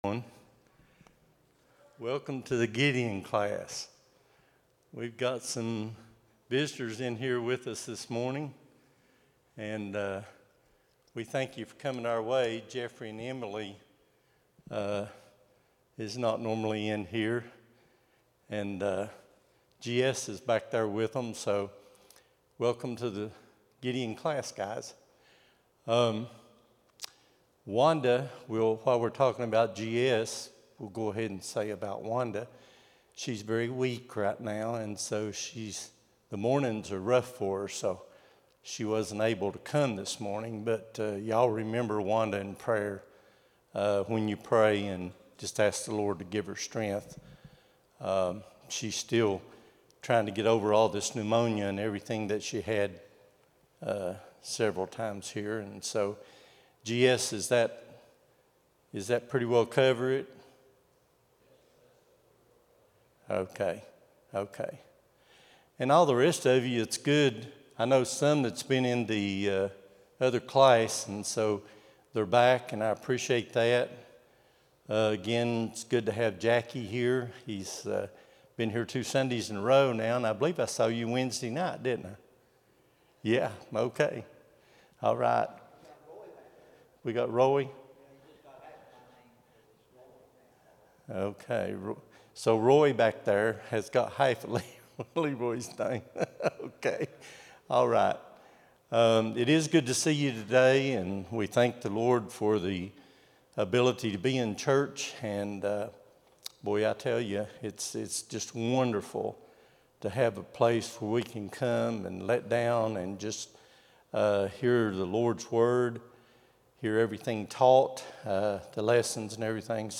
08-10-25 Sunday School | Buffalo Ridge Baptist Church